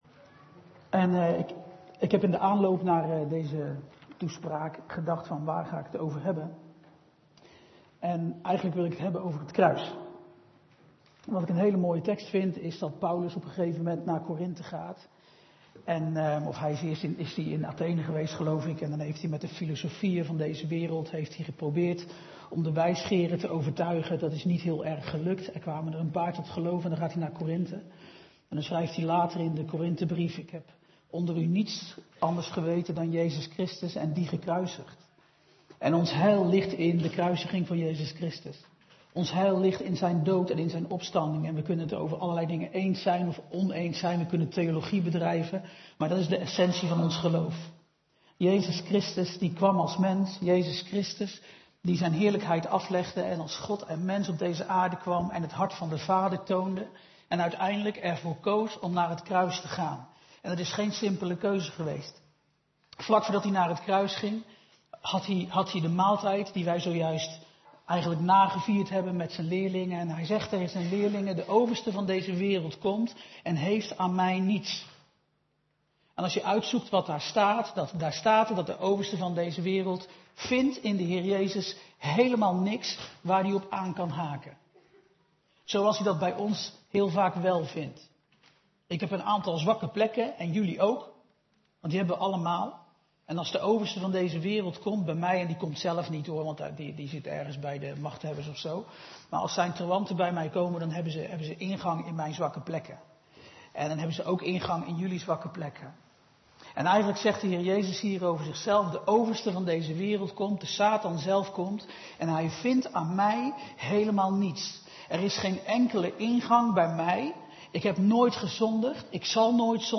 Toespraak van 21 juli: Het Kruis - De Bron Eindhoven